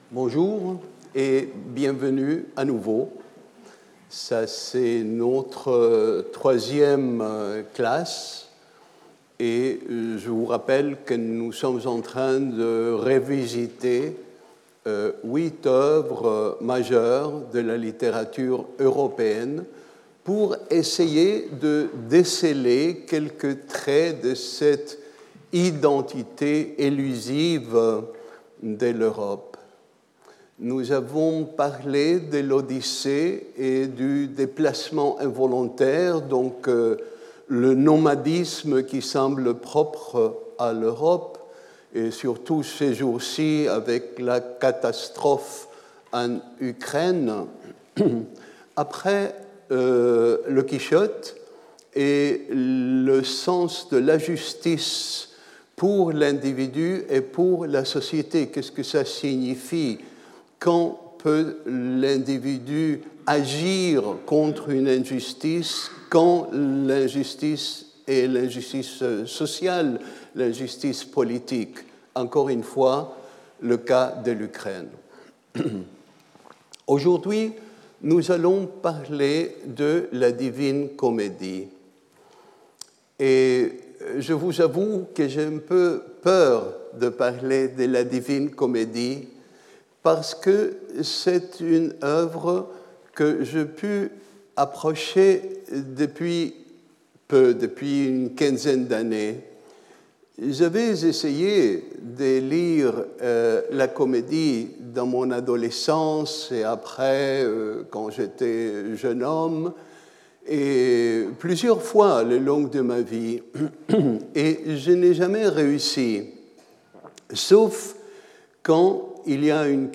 Alberto Manguel Professeur invité, Collège de France
Cours